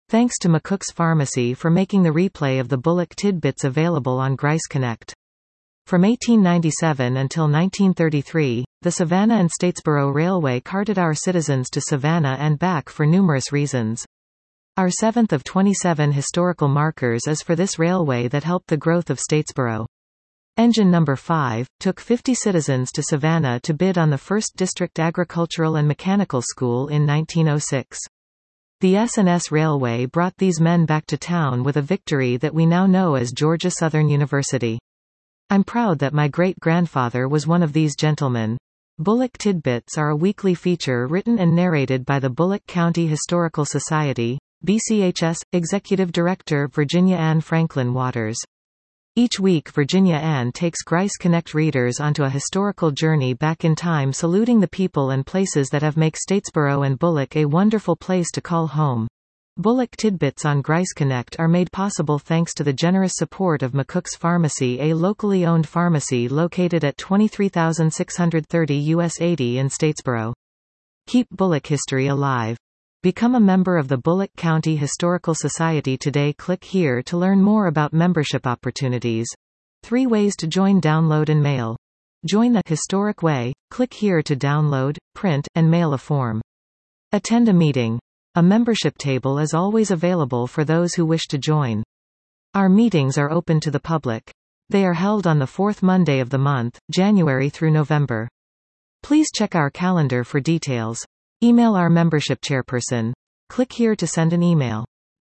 Bulloch Tidbits are a weekly feature written and narrated by the Bulloch County Historical Society